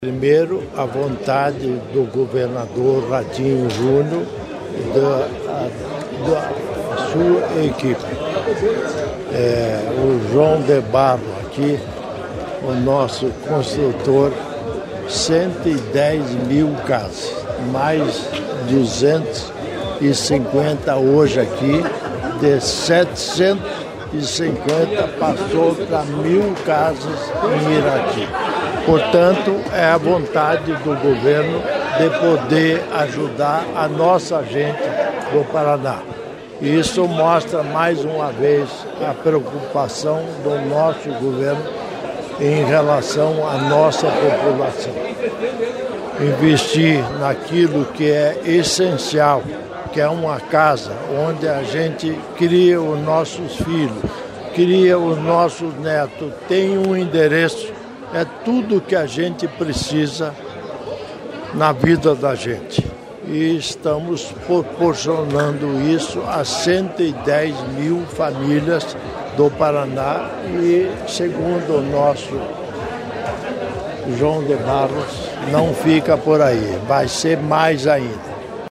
Sonora do governador em exercício Darci Piana sobre a entrega de casas próprias a 46 famílias de Irati